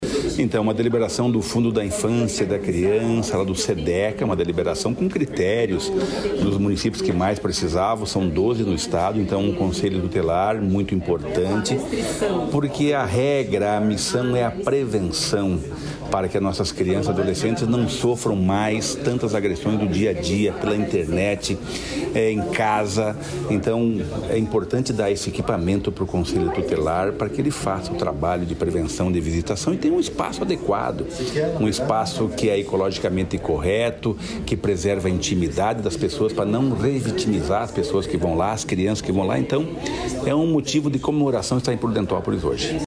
Sonora do secretário do Desenvolvimento Social e Família, Rogério Carboni, sobre as novas sedes do Conselho Tutelar